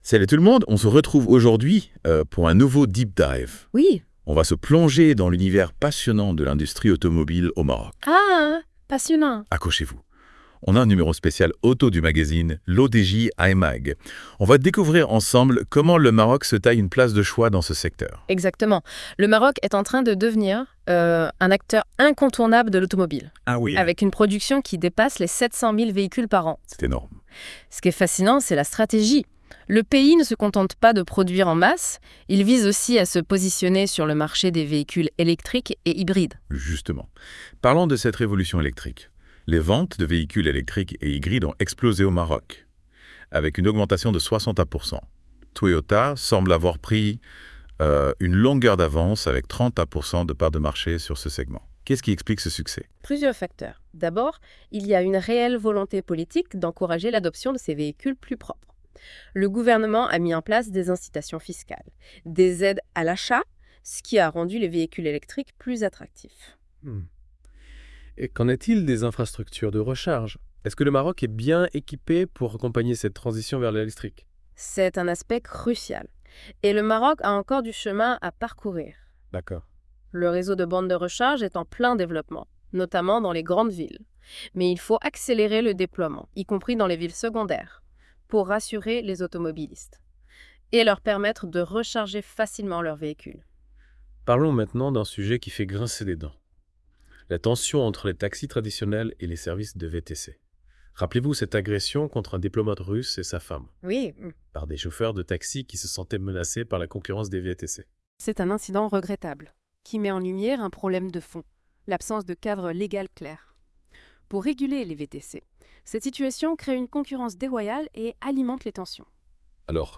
Ce sommaire résume les principaux articles et analyses présentés dans ce magazine spécial. Les chroniqueurs de la Web Radio R212 ont lus attentivement l'I-MAG Spécial Auto-Moto de L'ODJ Média et ils en ont débattu dans ce podcast IMAG Spécial Auto-Moto N°5 - Décembre 2024.wav (71.26 Mo) 1. Quelle est la situation actuelle du marché automobile marocain ?